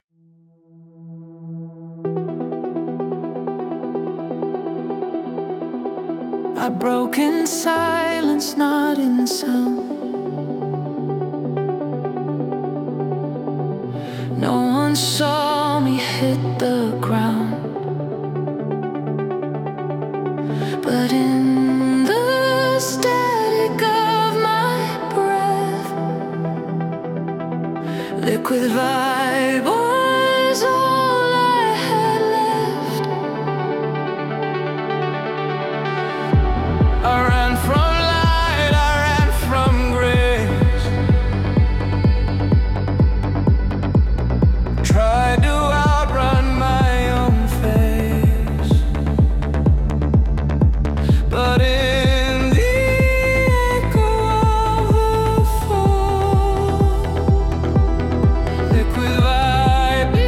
Enjoy a 1-Minute Sample – Purchase to Hear the Whole Track